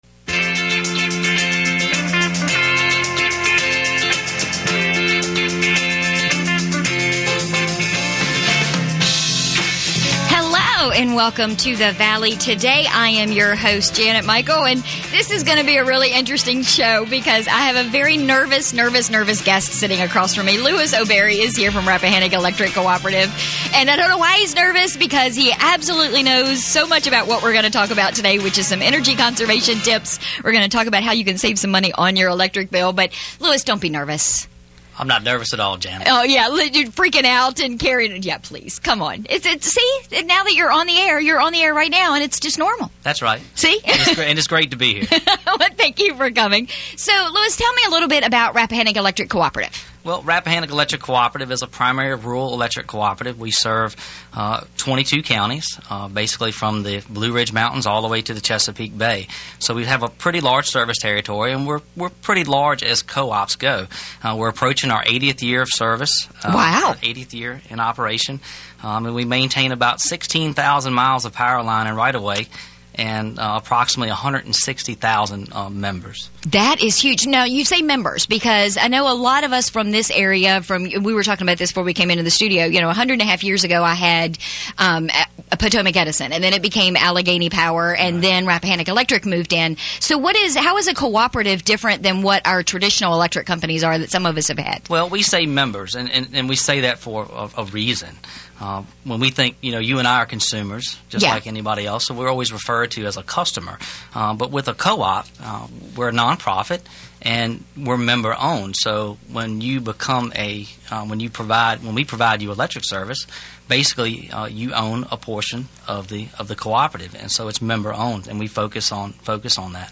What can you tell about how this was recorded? Our conversation continued even after we went off the air, but you can hear the whole thing on this podcast.